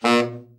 TENOR SN   4.wav